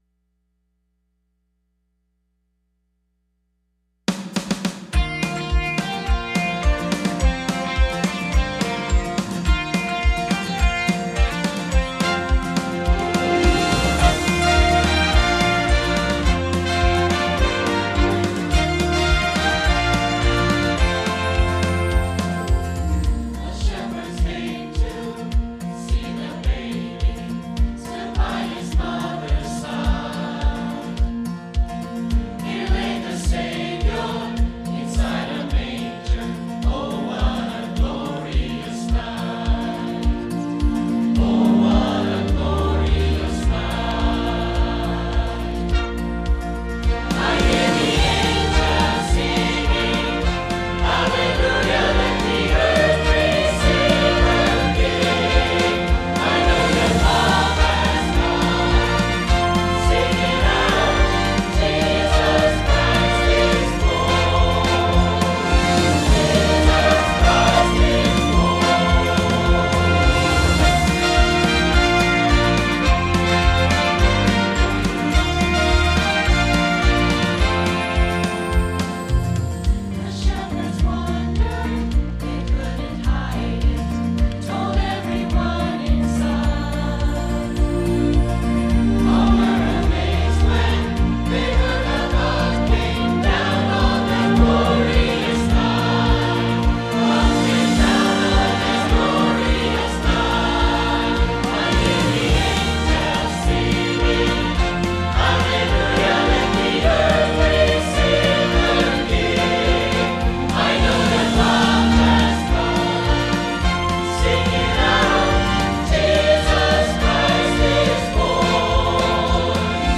2025 Includes Choir Presentation Advent “Love” Study Questions